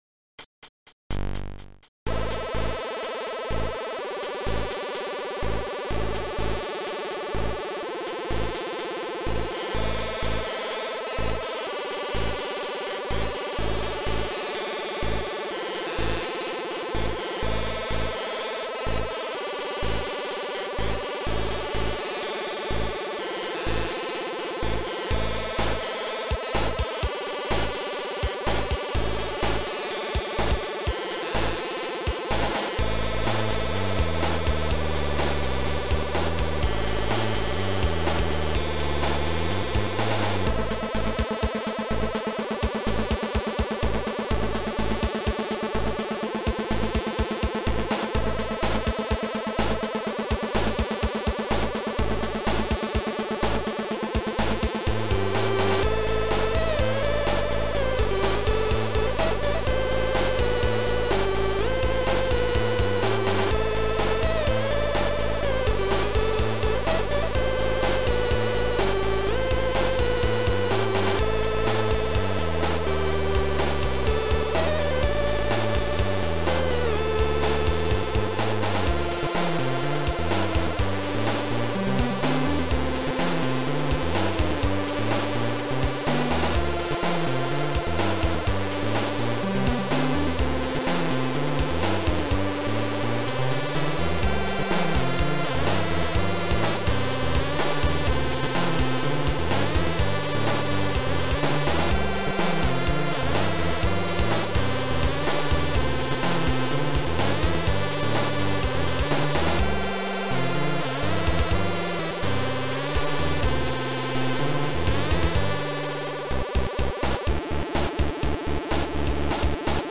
Sound Format: Noisetracker/Protracker
Sound Style: Chip